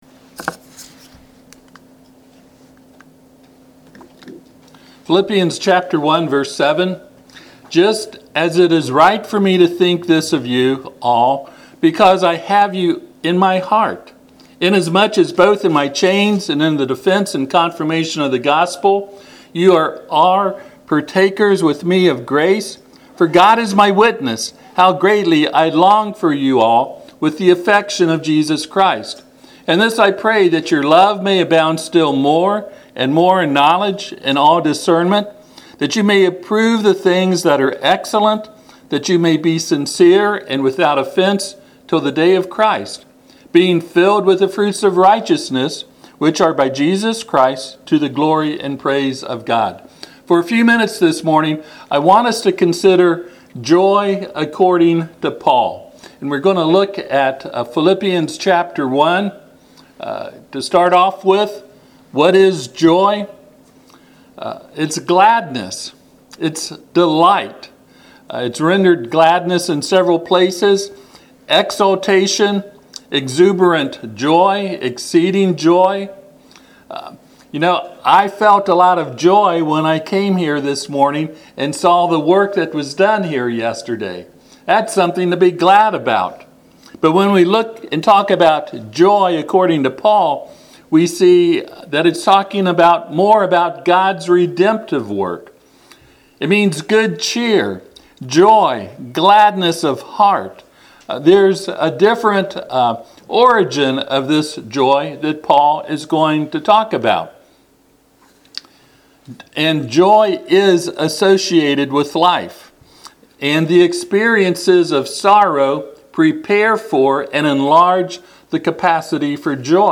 Passage: Philippians 1:7-8 Service Type: Sunday AM